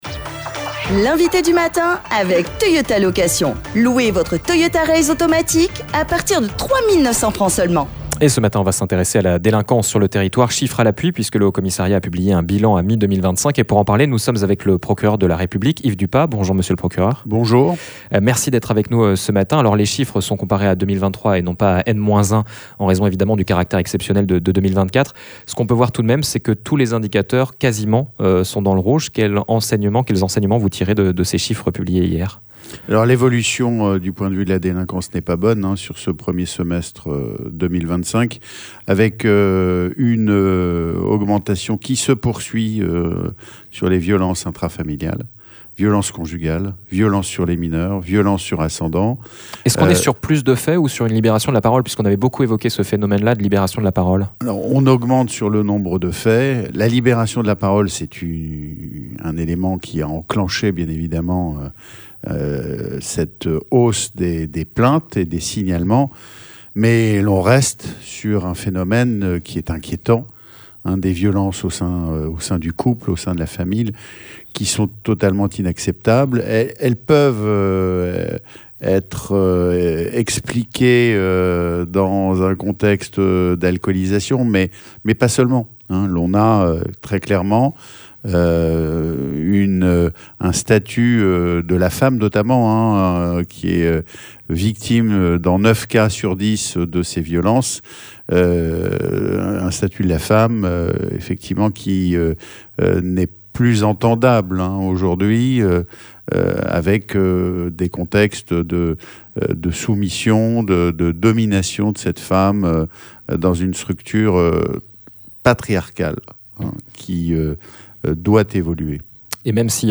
C'est le procureur de la République Yves Dupas qui était notre invité du matin à 7h30. Nous sommes revenus, avec lui, sur le bilan de la délinquance au premier semestre de l’année 2025. L’occasion également d’aborder les réponses que la justice peut apporter à ces faits de violences.